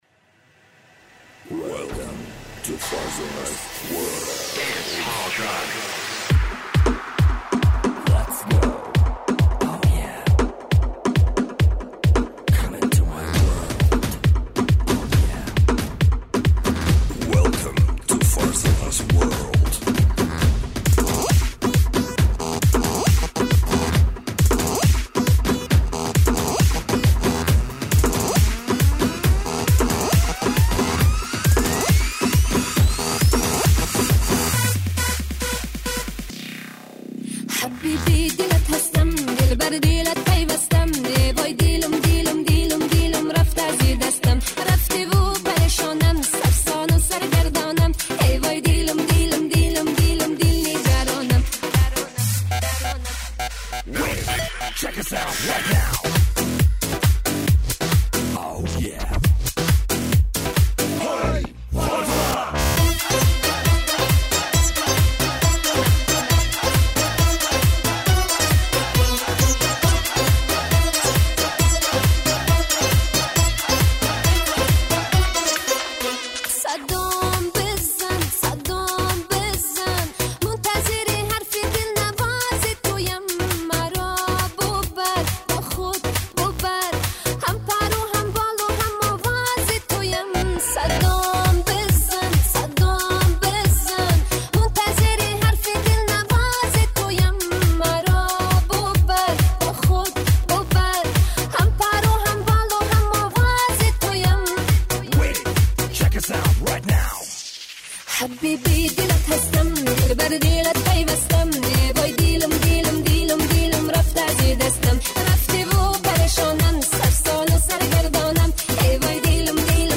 Категория: Эстрада